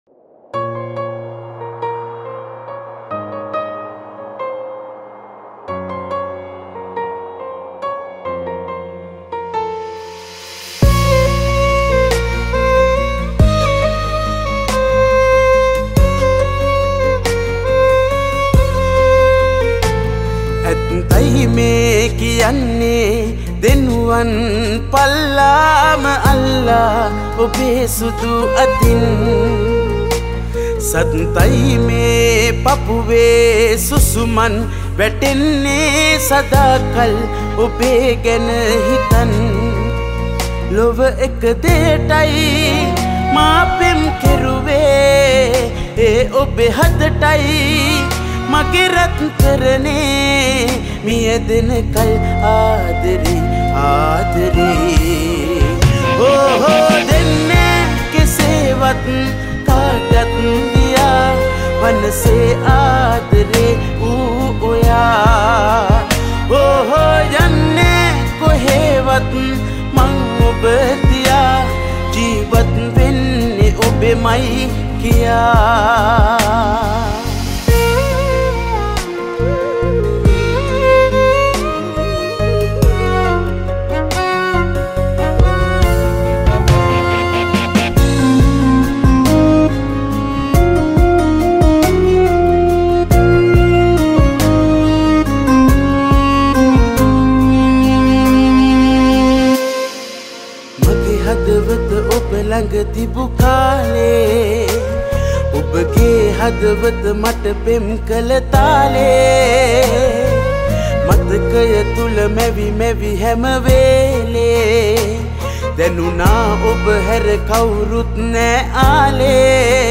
This a very romantic song.